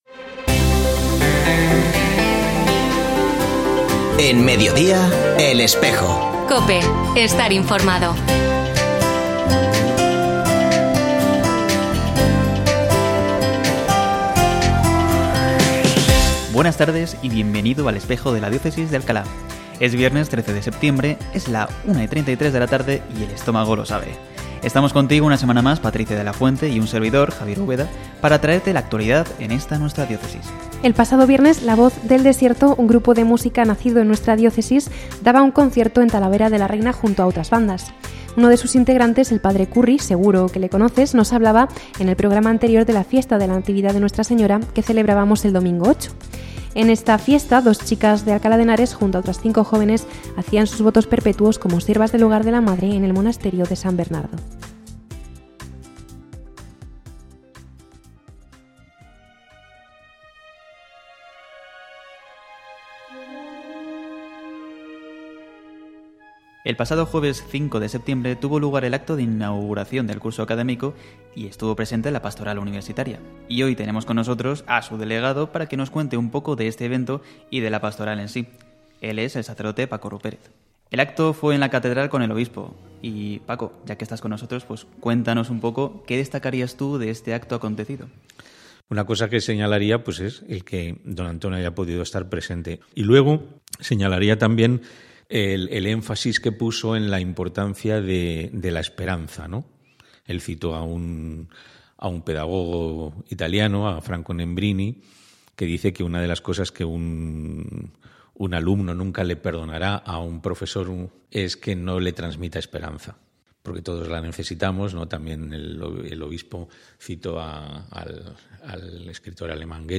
Escucha otras entrevistas de El Espejo de la Diócesis de Alcalá
Como cada viernes, se ha vuelto a emitir hoy, 13 de septiembre de 2024, en radio COPE. Este espacio de información religiosa de nuestra diócesis puede escucharse en la frecuencia 92.0 FM, todos los viernes de 13.33 a 14 horas.